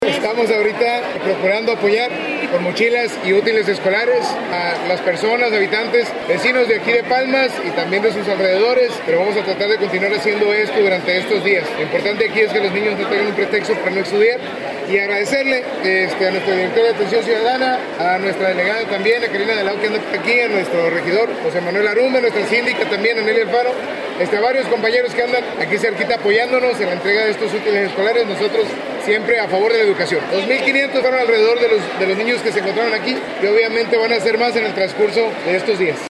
alcalde Christian Agúndez
En su mensaje, el alcalde Christian Agúndez destacó que la educación es una prioridad para el Ayuntamiento de Los Cabos y que este tipo de acciones forman parte de la transformación social que vive el municipio.